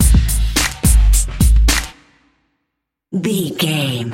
Aeolian/Minor
drum machine
synthesiser
drums
funky